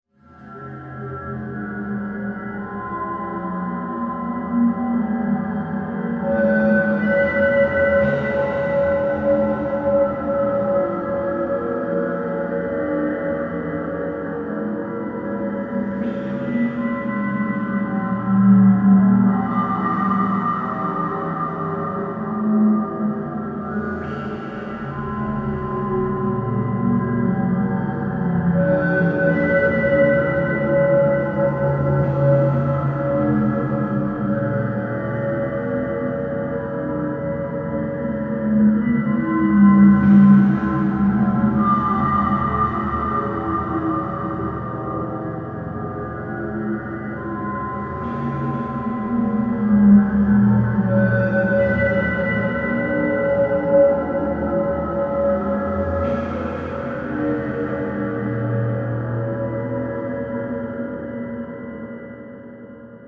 Scary Ambience 2 - Botón de Efecto Sonoro